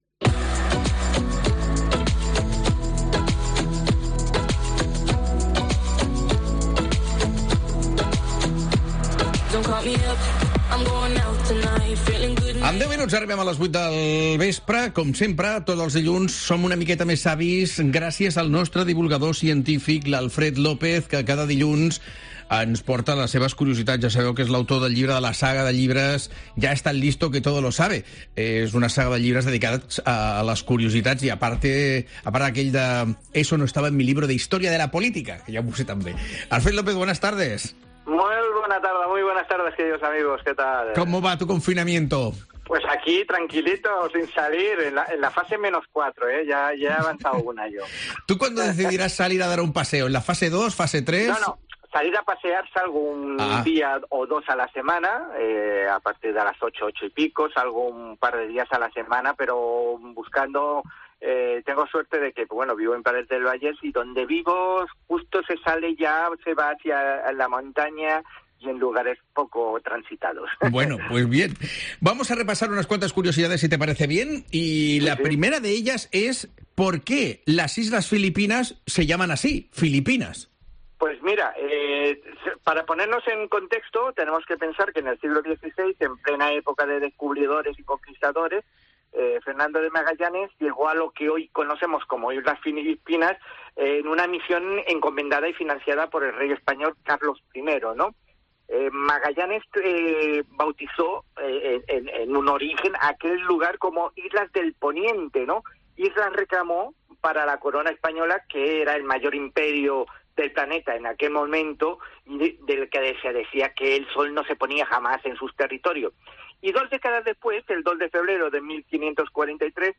Cada Lunes nos explica tres curiosidades en La Linterna Catalunya.